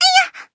文件 文件历史 文件用途 全域文件用途 Chorong_dmg_01.ogg （Ogg Vorbis声音文件，长度0.5秒，150 kbps，文件大小：10 KB） 源地址:地下城与勇士游戏语音 文件历史 点击某个日期/时间查看对应时刻的文件。